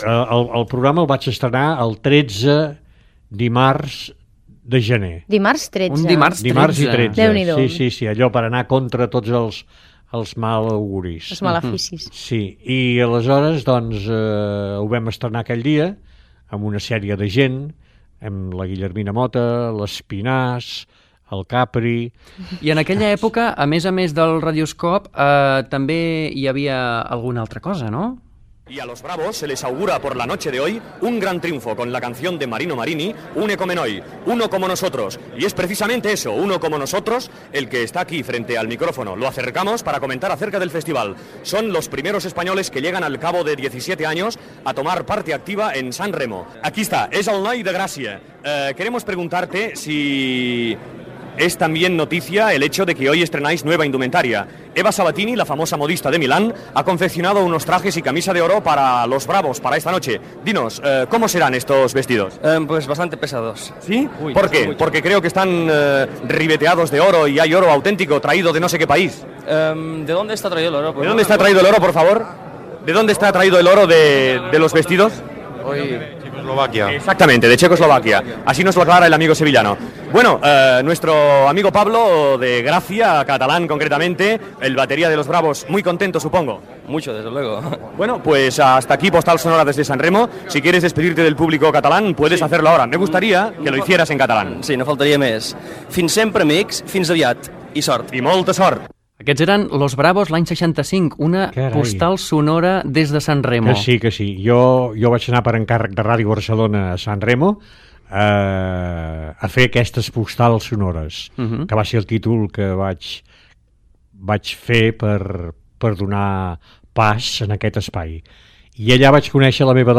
Entrevista a Salvador Escamilla sobre la seva trajectòria radiofònica.